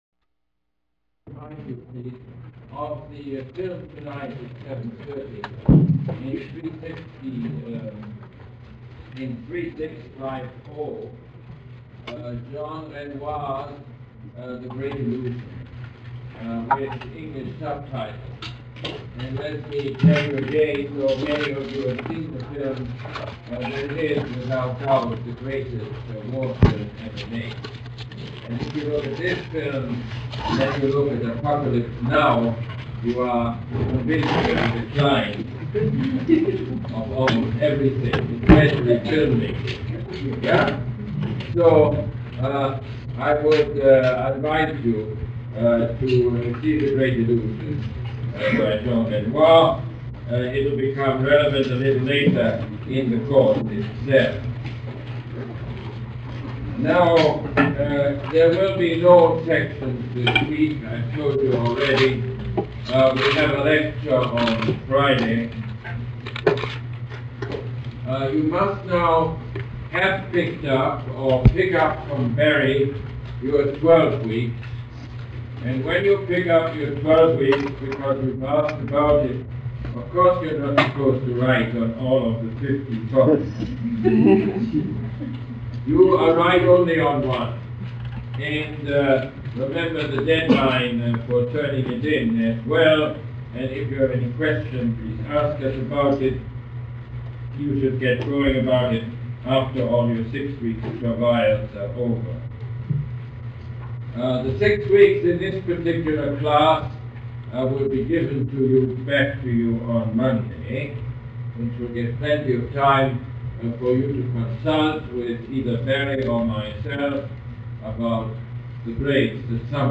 Lecture #11 - October 17, 1979